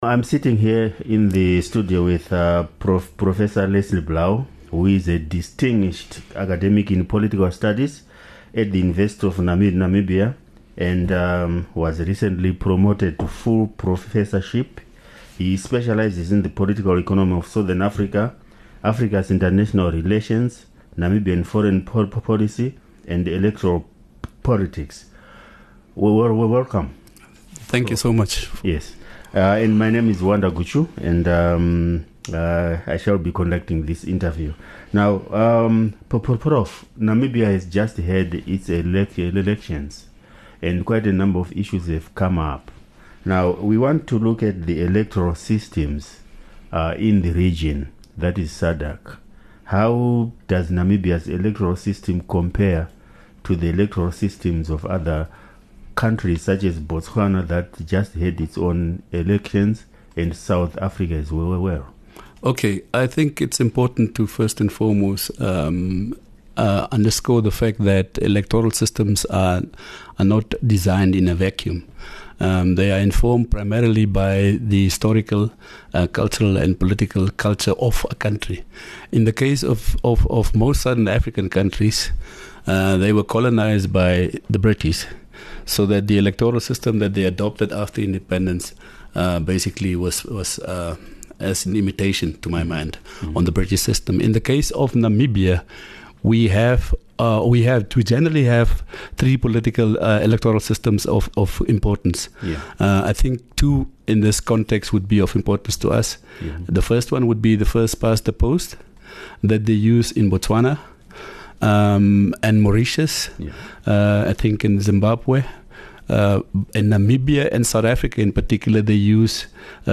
Post Election Chat